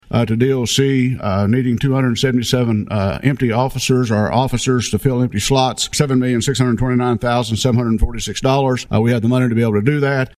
CLICK HERE to listen to commentary from Senator Roger Thompson.